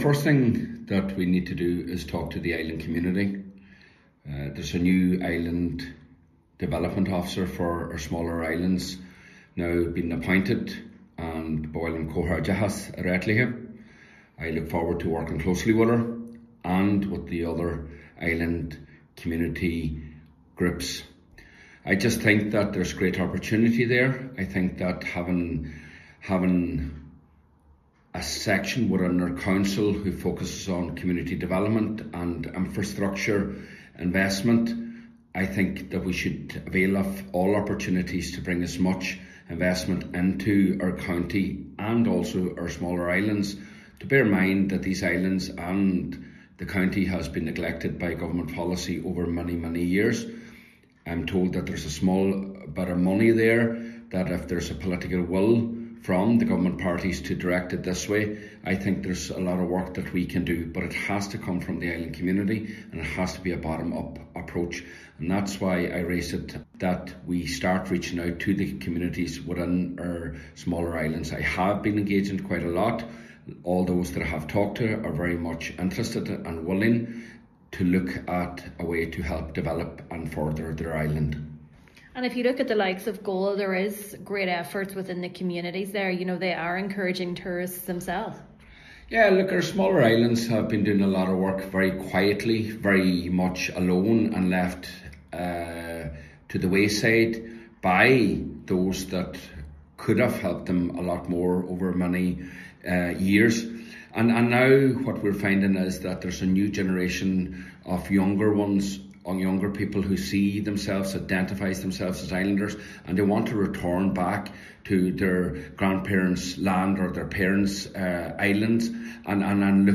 Councillor MacGiolla Easbuig says public engagement needs to be central to the further development of the islands: